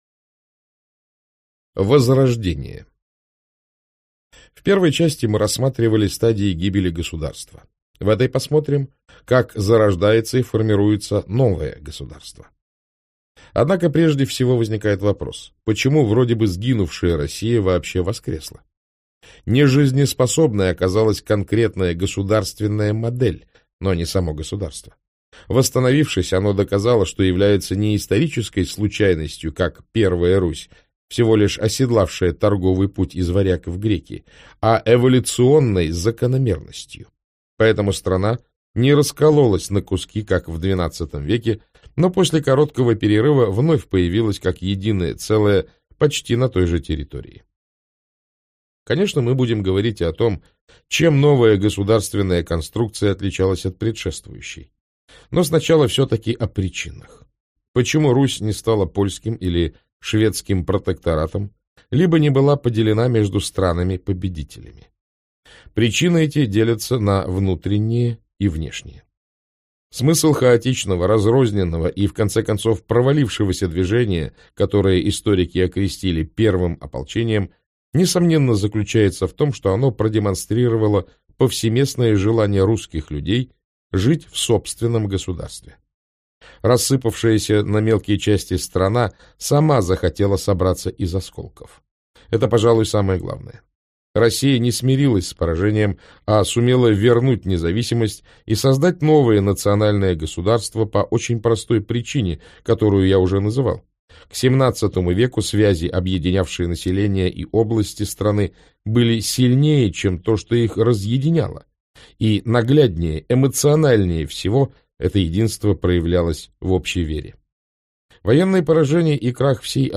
Аудиокнига Между Европой и Азией.